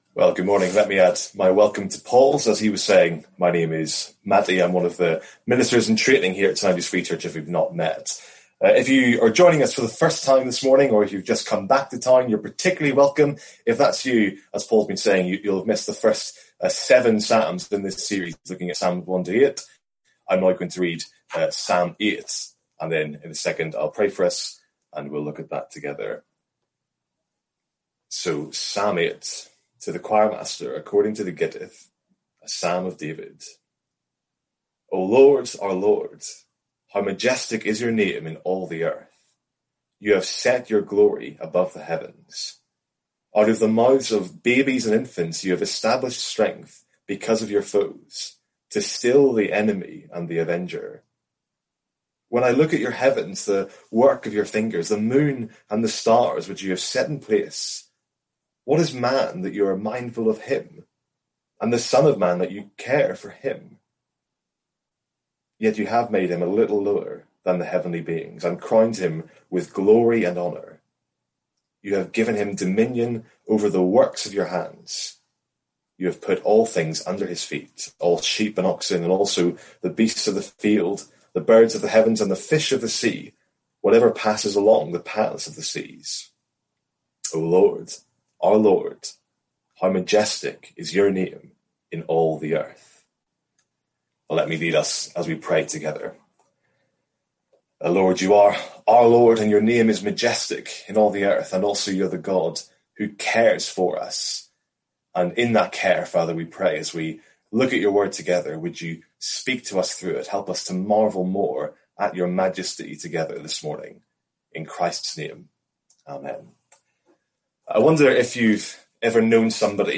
Sermons | St Andrews Free Church
From our morning series in the Psalms.